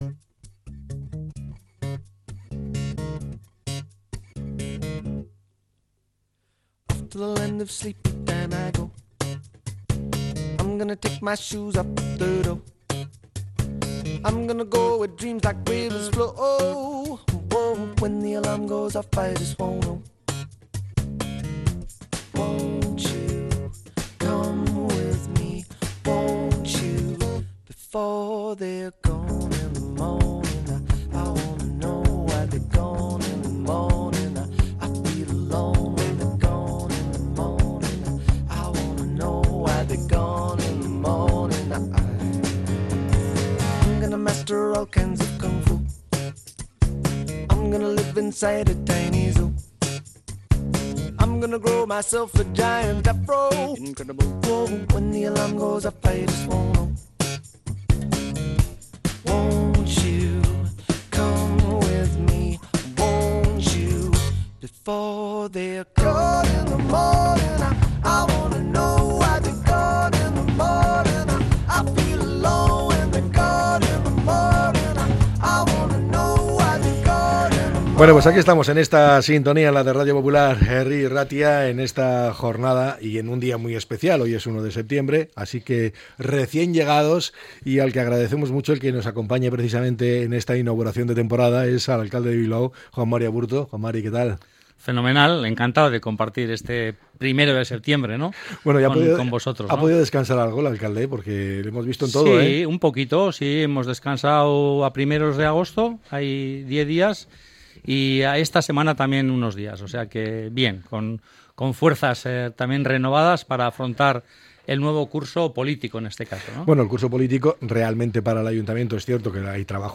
Entrevista con el alcalde de Bilbao, Juan Mari Aburto, en la apertura de un nuevo curso político